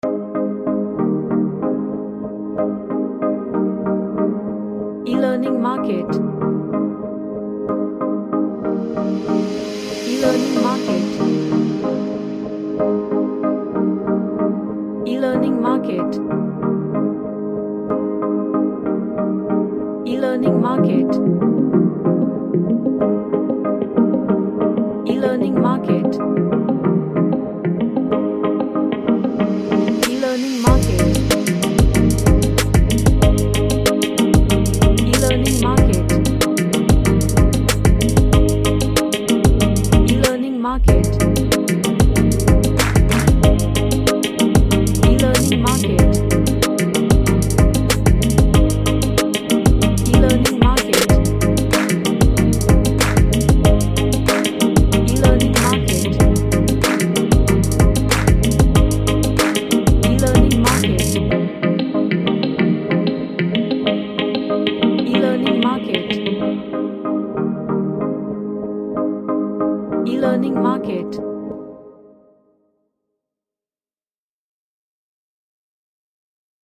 An uplifting indie track
Gentle / Light